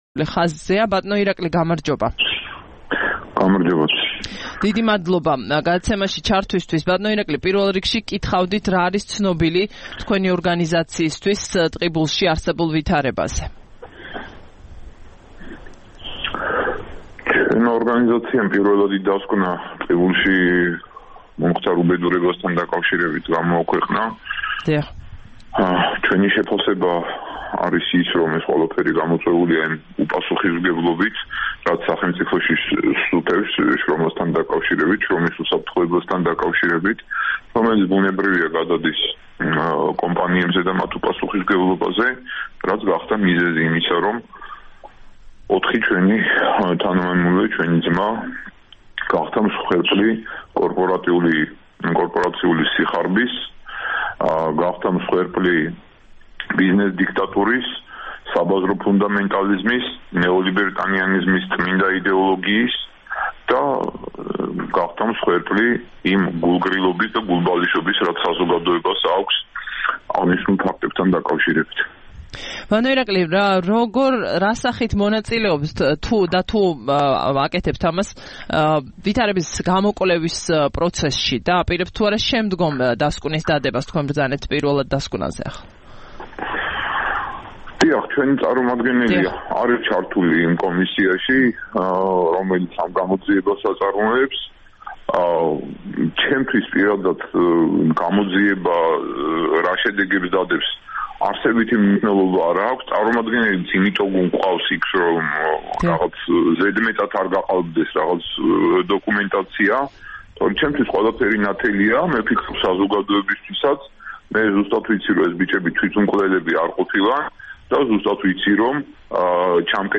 11 მაისს რადიო თავისუფლების "დილის საუბრებში" ტელეფონით ჩაერთო